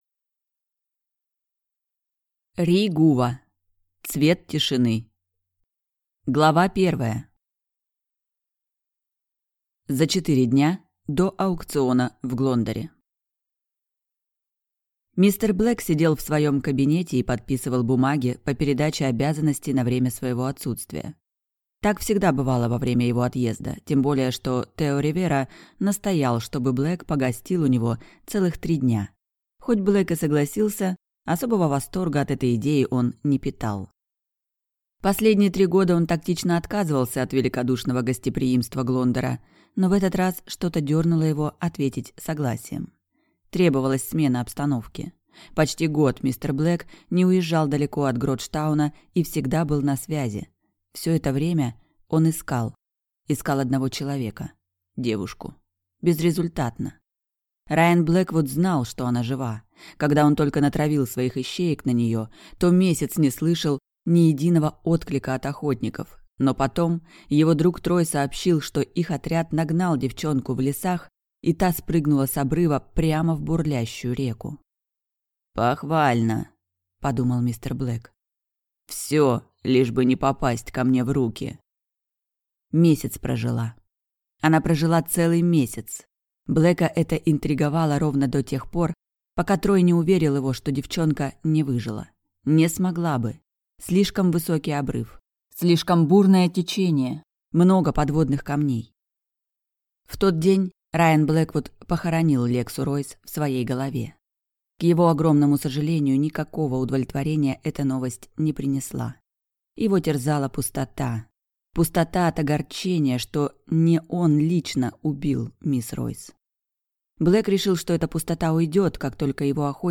Аудиокнига Цвет тишины | Библиотека аудиокниг